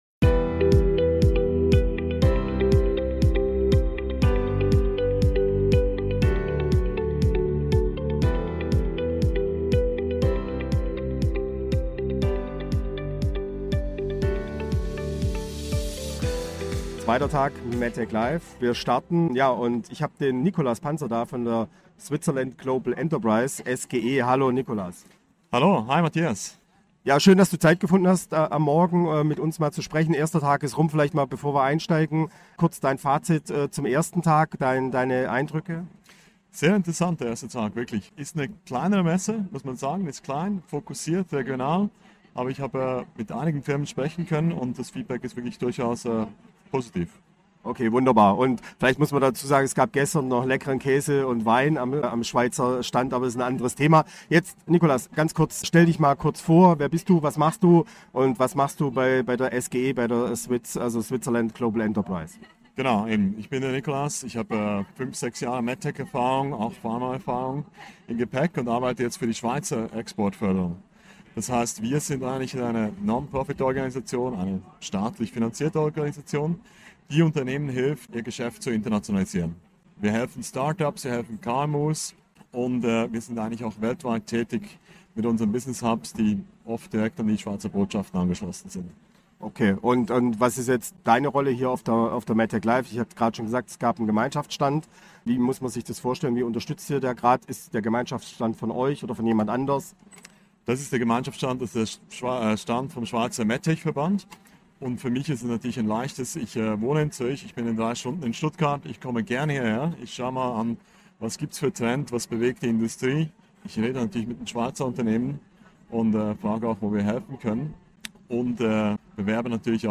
In dieser Folge des Rocketing Healthcare Podcasts bringen wir euch spannende Gespräche direkt von der MedTecLive.